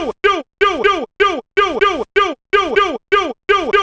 cch_vocal_loop_doit_125.wav